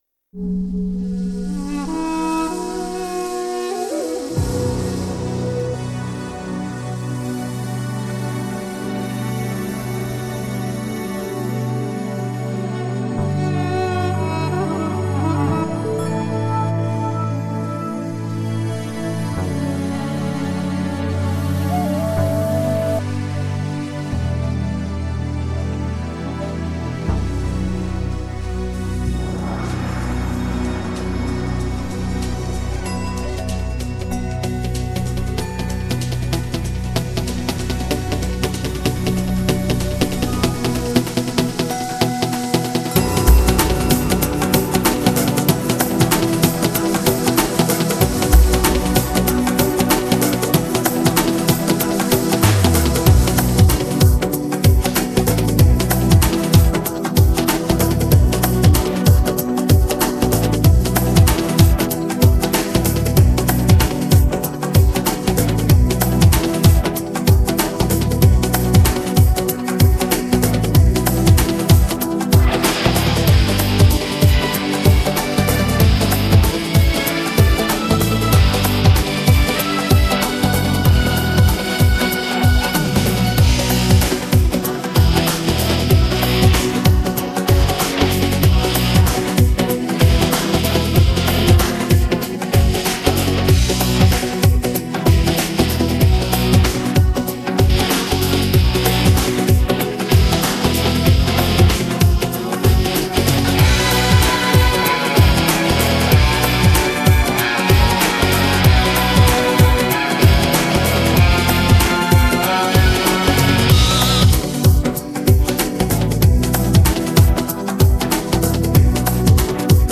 Идеально для караоке, репетиций, концертов и творчества.